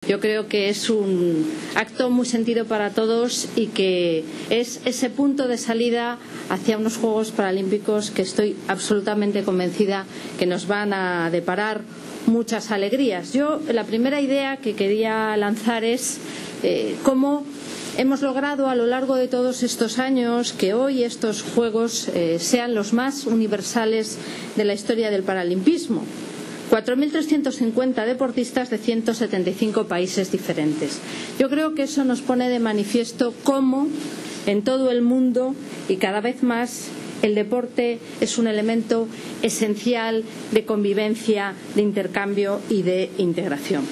Autoridades y miembros del equipo paralímpico posan en la despedida ante la sede del Conejo Superior de DeportesSAR la infanta doña Elena y la vicepresidenta del Gobierno en funciones, Soraya Sáenz de Santamaría, presidieron en Madrid el multitudinario acto de despedida del Equipo Paralímpico Español que viaja a Río de Janeiro para participar en los Juegos Paralímpicos, que se celebran entre el 7 y el 18 de septiembre.